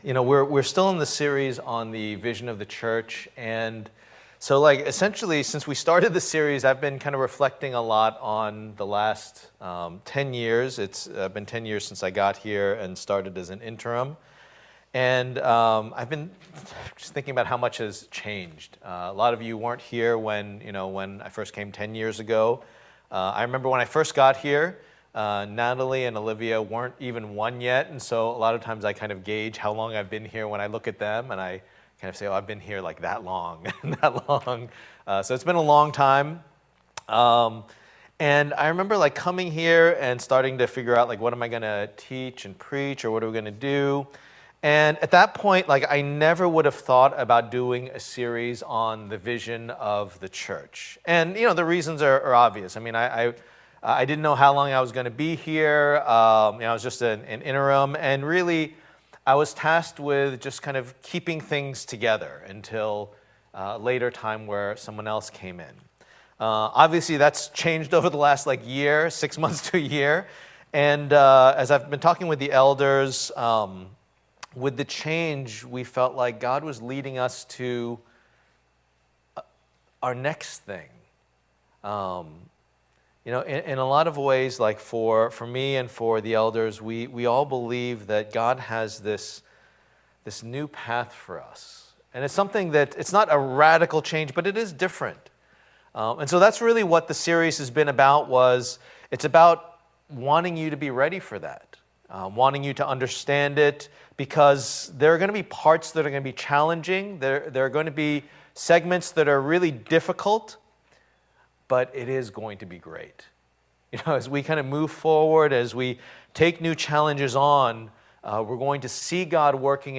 Passage: 1 Peter 2:1-12 Service Type: Lord's Day